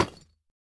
Minecraft Version Minecraft Version snapshot Latest Release | Latest Snapshot snapshot / assets / minecraft / sounds / block / deepslate_bricks / place3.ogg Compare With Compare With Latest Release | Latest Snapshot